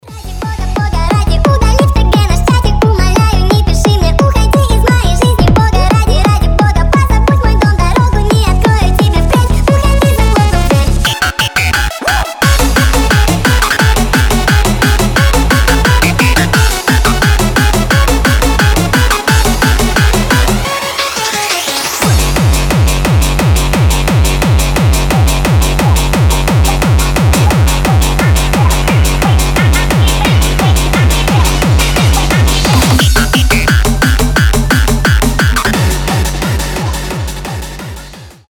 Танцевальные рингтоны
Рейв , Hardstyle
Hyperpop